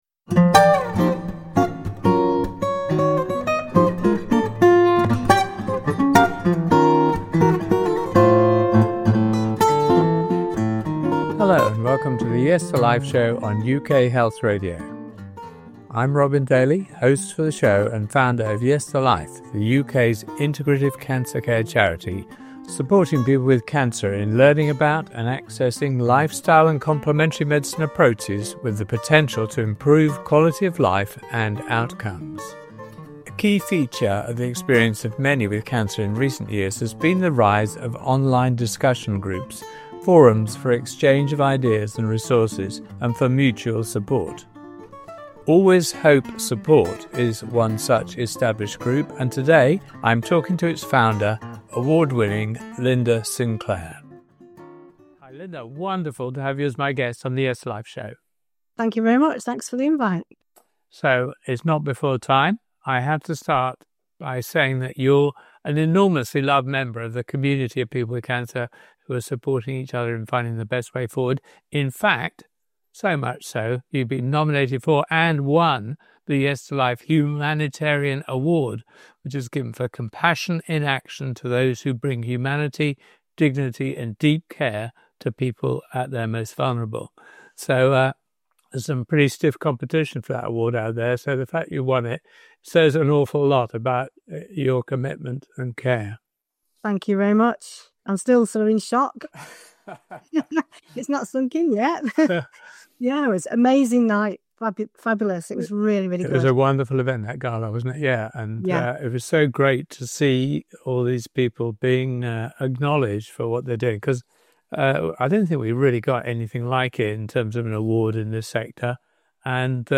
Don’t miss this moving conversation.